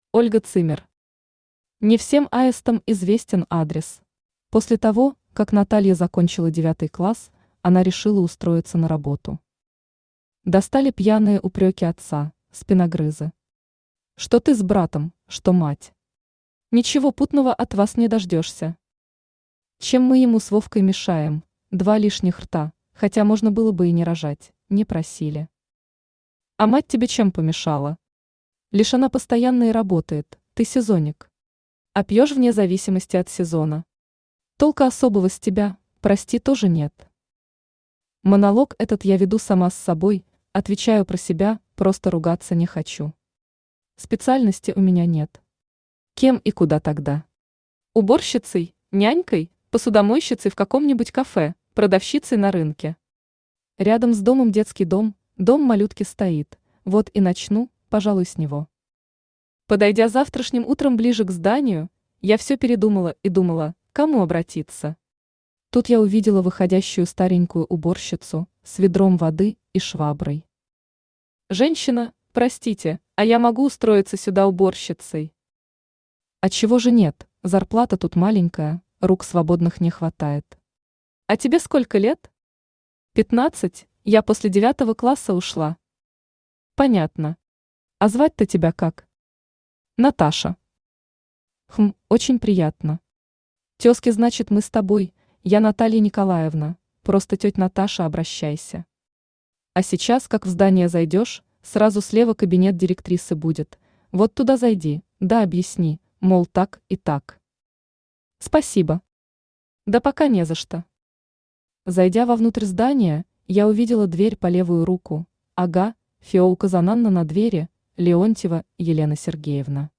Аудиокнига Не всем аистам известен адрес | Библиотека аудиокниг
Читает аудиокнигу Авточтец ЛитРес.